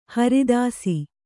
♪ haridāsi